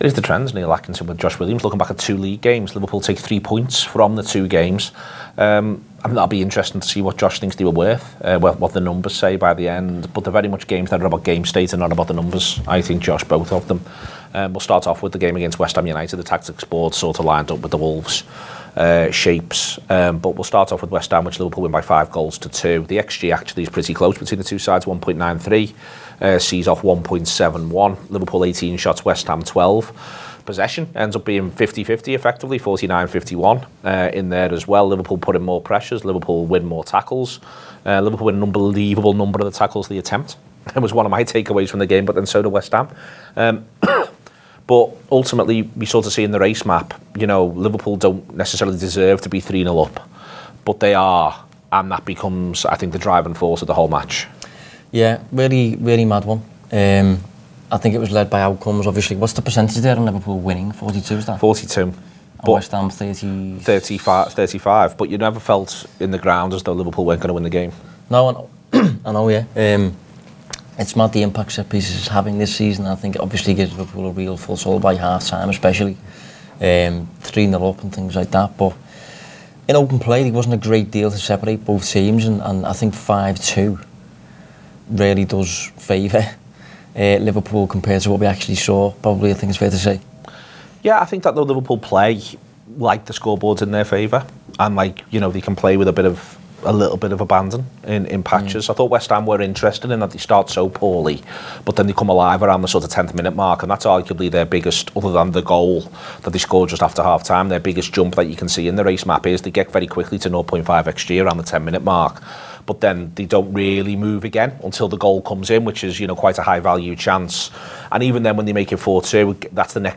Below is a clip from the show – subscribe to The Anfield Wrap for more in-depth analysis